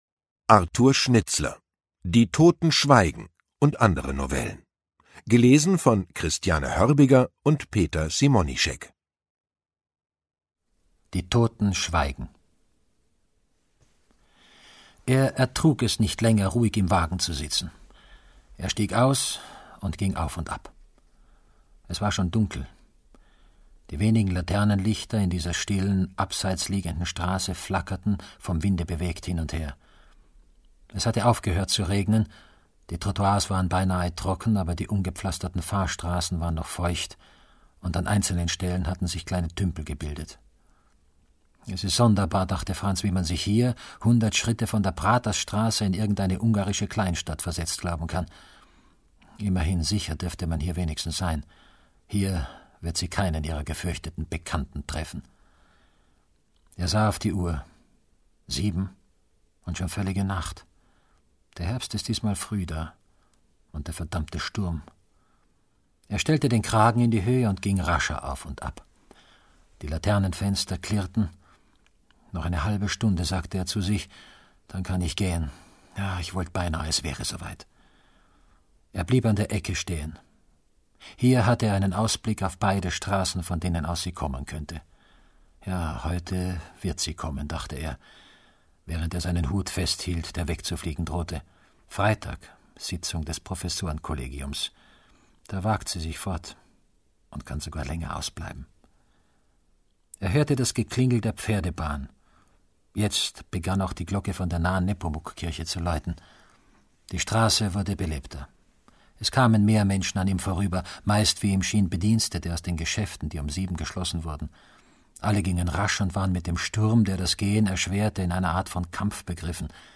Ungekürzte Lesungen mit Christiane Hörbiger und Peter Simonischek (1 mp3-CD)
Christiane Hörbiger, Peter Simonischek (Sprecher)